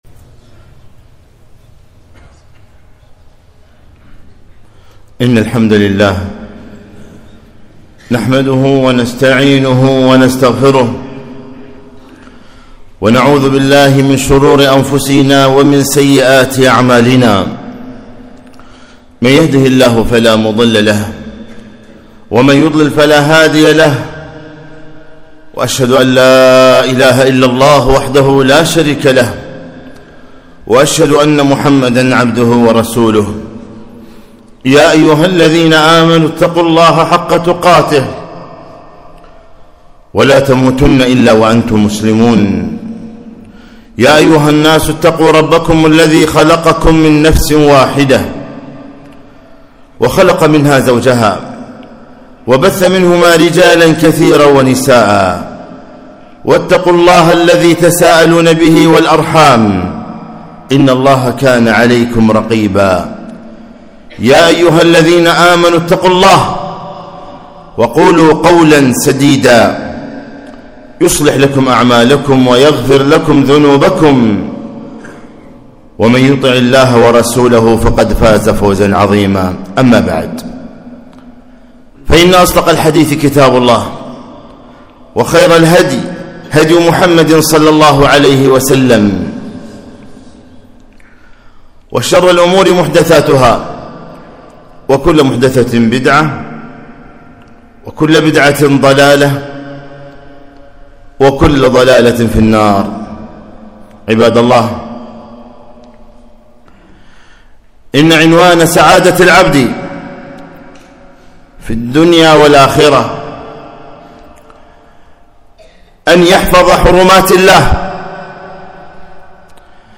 خطبة - احفظ الله يحفظك